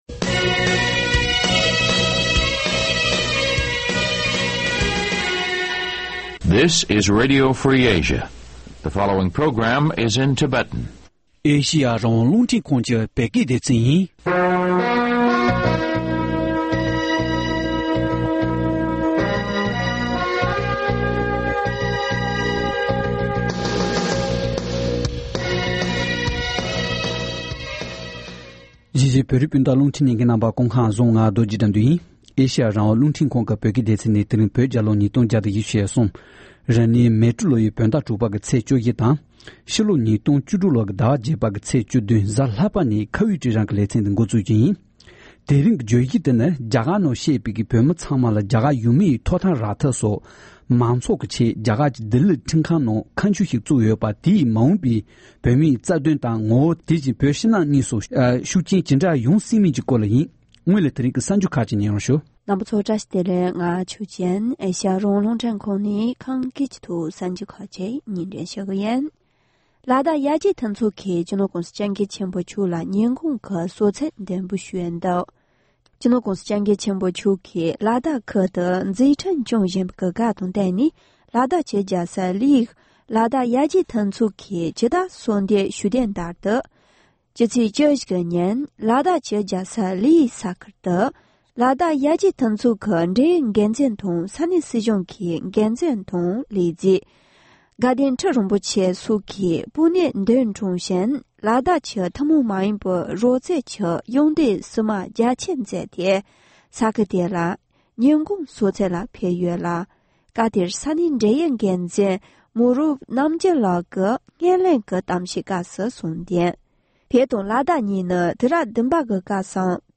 བགྲོ་གླེང་གནང་བ་ཞིག་གསན་རོགས་གནང་།